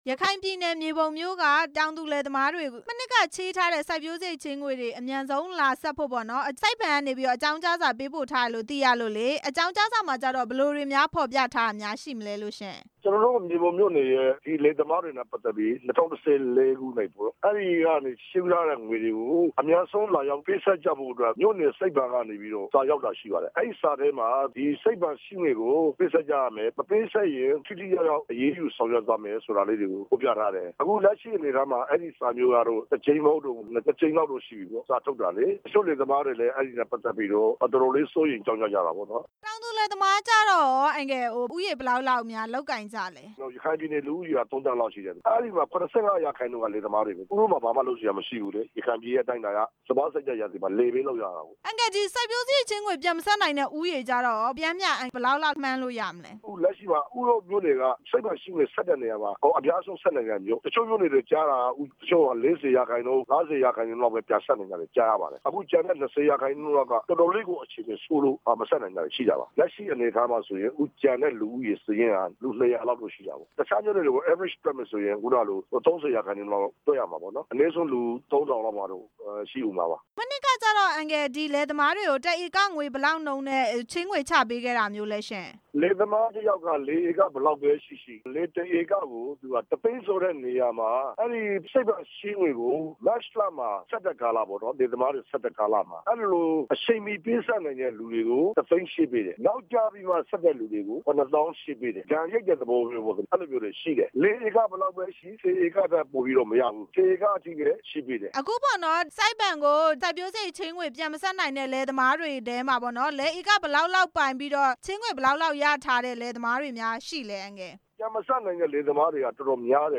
စိုက်ပျိုးစရိတ်ချေးငွေကိစ္စ ပြည်နယ်လွှတ်တော် ကိုယ်စားလှယ်နဲ့ မေးမြန်းချက်